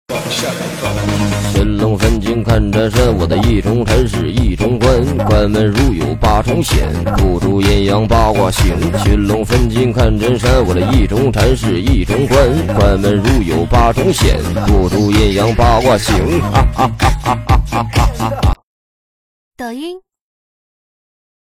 本铃声大小为87.9KB，总时长21秒，属于DJ分类。